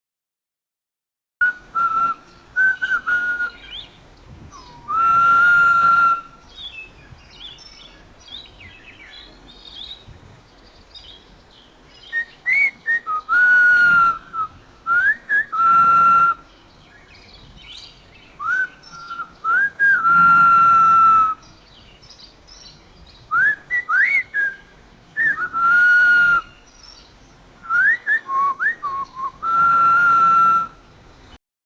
音频生成示例
• 人的口哨声与自然鸟鸣声（The whistles of man and the birds of nature.）
人的口哨声与自然的鸟鸣声（The-whistles-of-man-and-the-birds-of-nature.）-1.mp3